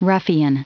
Prononciation du mot ruffian en anglais (fichier audio)
Prononciation du mot : ruffian